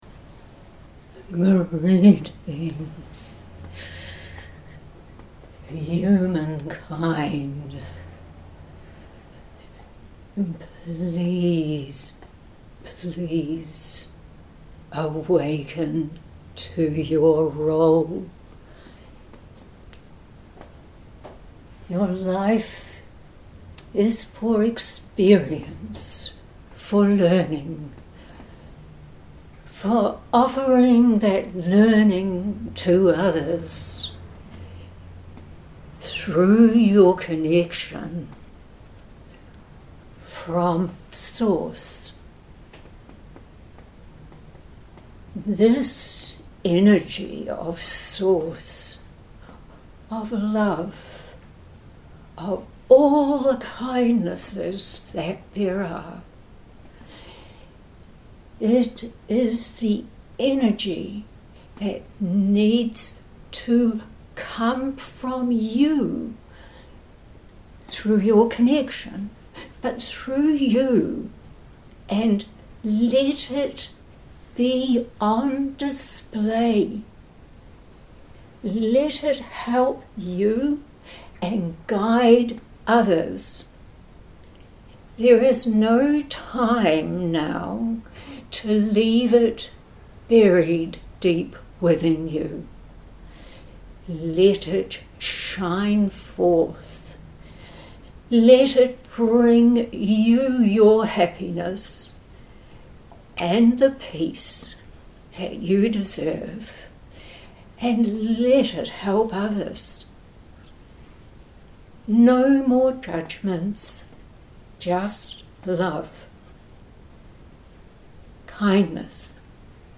Posted in Audio recording, Channelled messages, Metaphysical, Spirituality, Trance medium